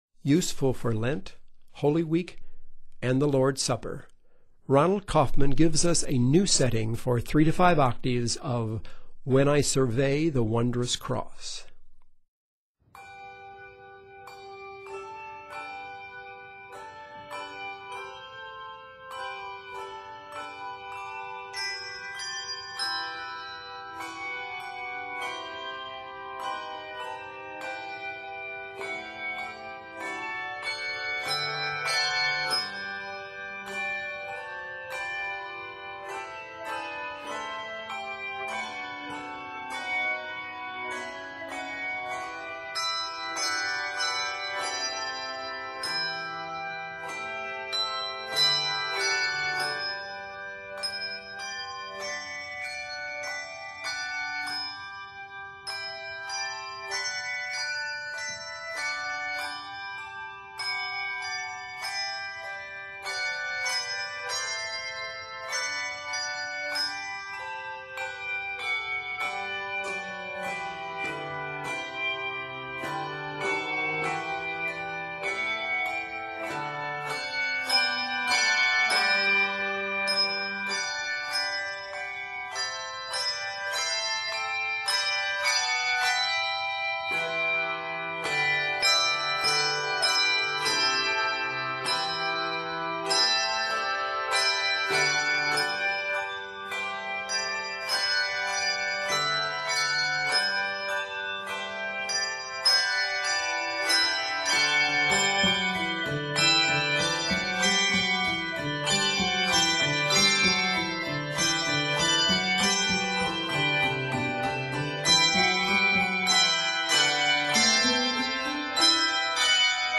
3-5 octave handbells
G Major